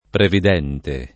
vai all'elenco alfabetico delle voci ingrandisci il carattere 100% rimpicciolisci il carattere stampa invia tramite posta elettronica codividi su Facebook previdente [ previd $ nte ] (lett. preveggente [ preve JJ$ nte ]) agg.